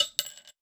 weapon_ammo_drop_05.wav